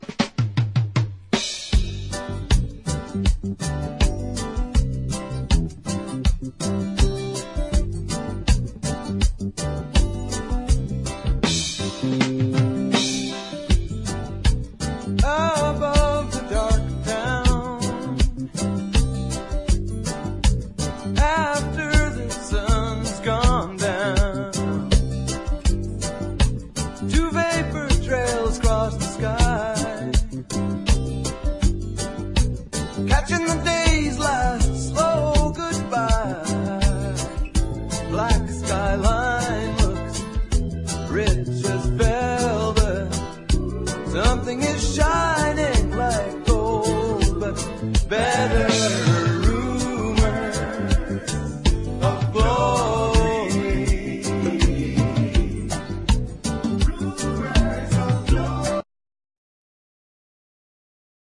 ROCK / 80'S/NEW WAVE. / NEW WAVE / POST PUNK
NEW WAVE / POST PUNK重要バンドだらけの、入門編にも最適なライヴ・オムニバス！